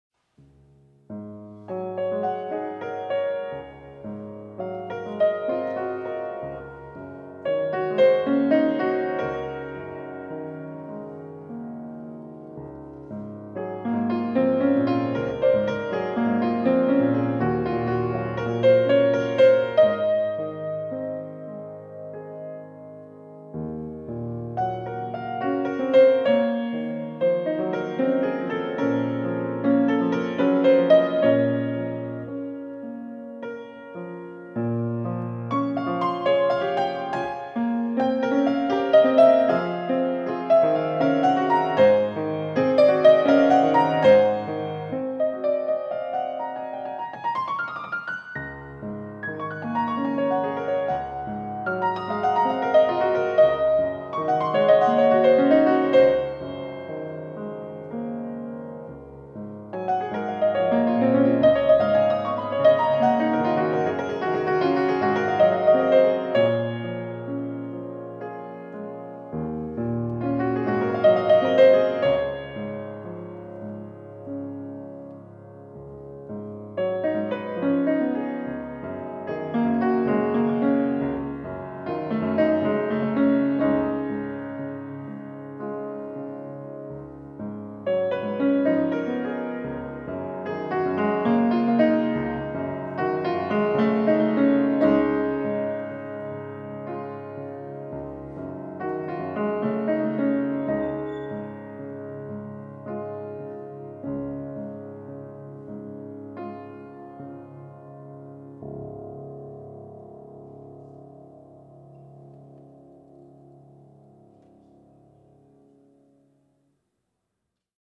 Sprievodný vokál a recitál